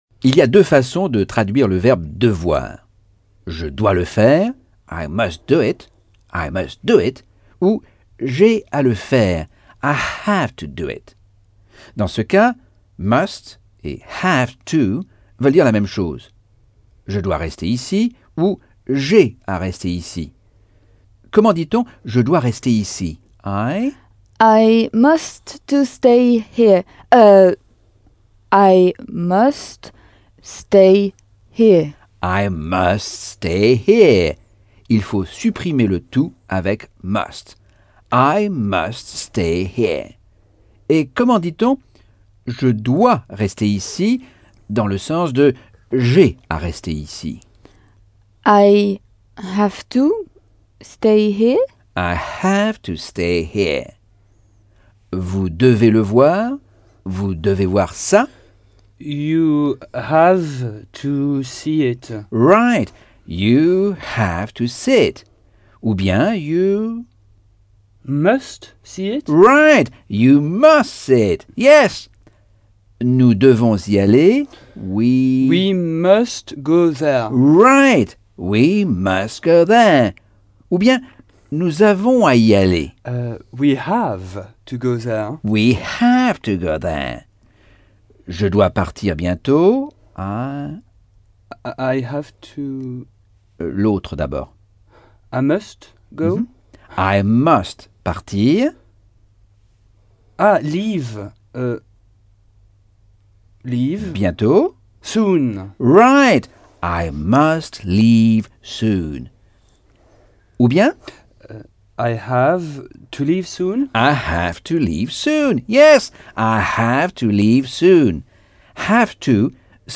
Leçon 7 - Cours audio Anglais par Michel Thomas